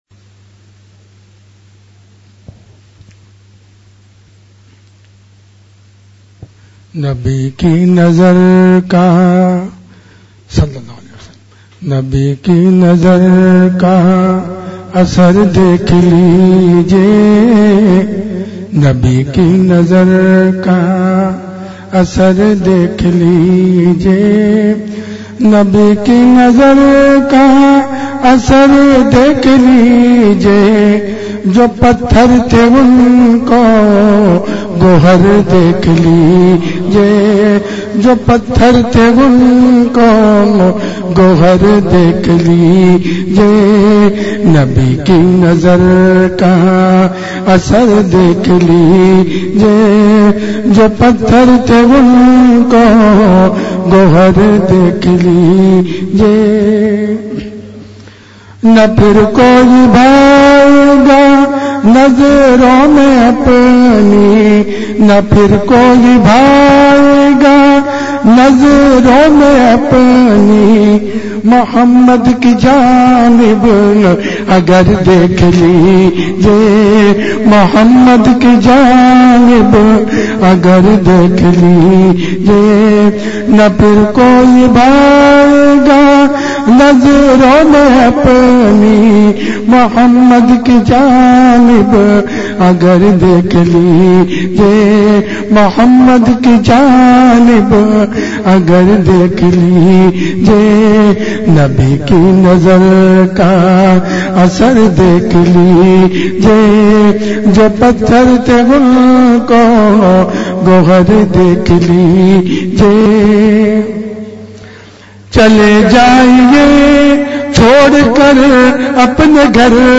ایک خاص بیان
اورخانقاہ میں انہوں نے نہایت جذب کے انداز میں یہ واقعہ بیان کیا ۔